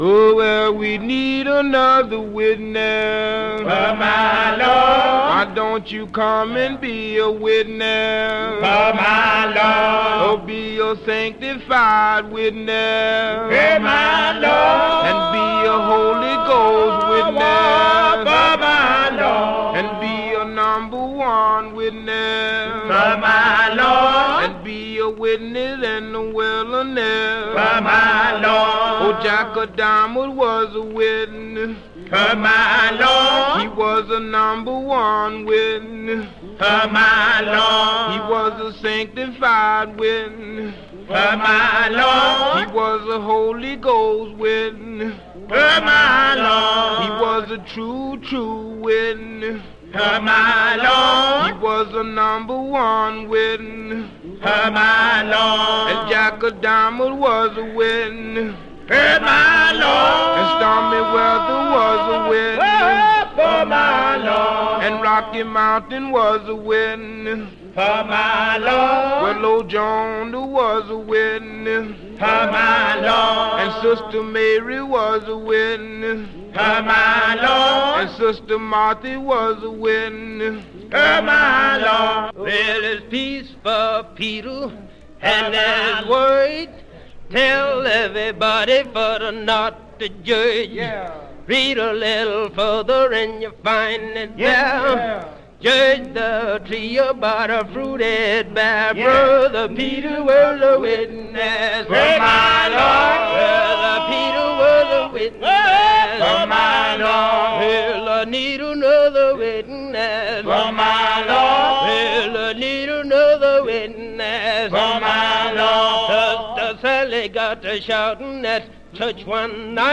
Prison work songs